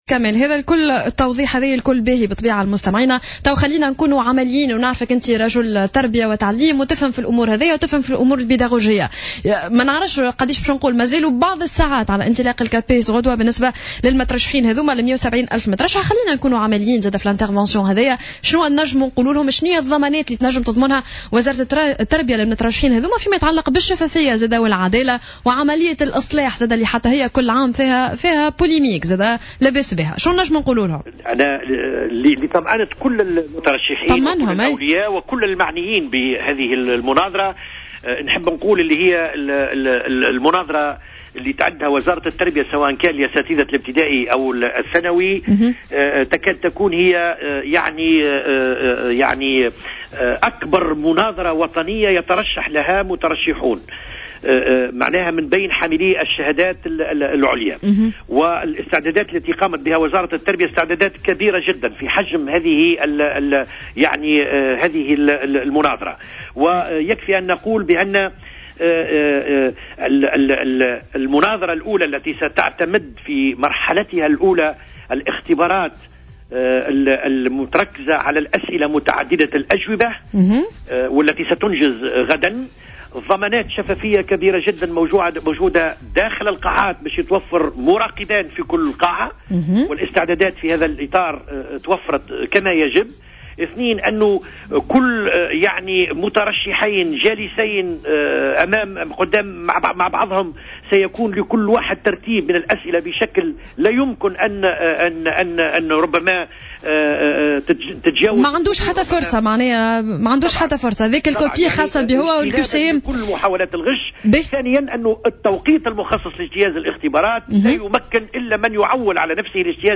قال كمال الحجام المدير العام للمرحلة الابتدائية في مداخلة له صباح اليوم السبت على "جوهرة أف أم" إن منظمة "أنا يقظ" ستشارك في عملية مراقبة اختبارات مناظرتي المعلمين والأساتذة المزمع إجراءها غدا الأحد 10 ماي 2015 ويشارك فيها حوالي 172 ألف مترشح.